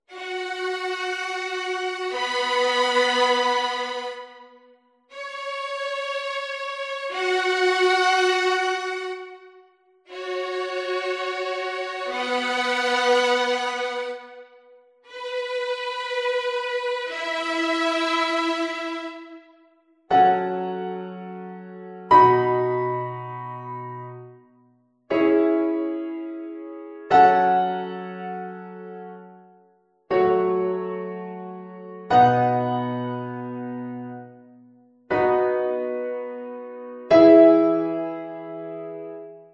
Uitgevoerd door Deutsche Kammerphilharmonie Bremen, o.l.v. Paavo Järvi.